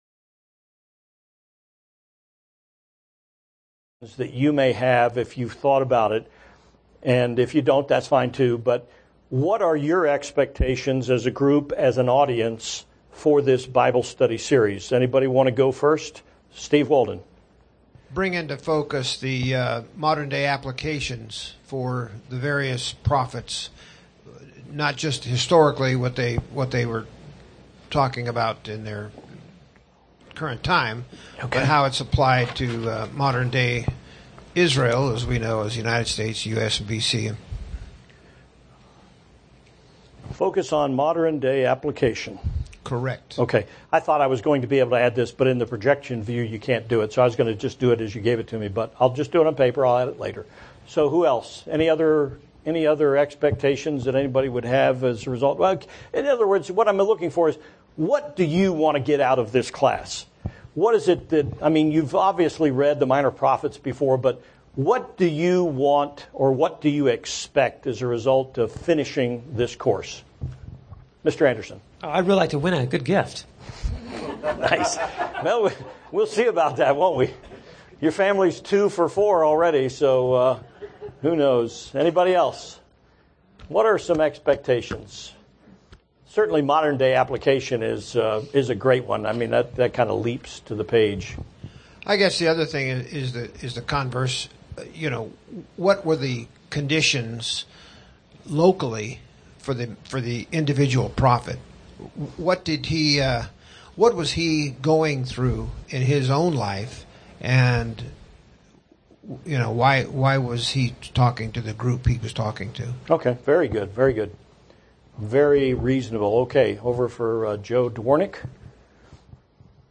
This Bible study is an introduction to a series on the Minor Prophets. The studies will be given based on the chronology of the prophets' lives.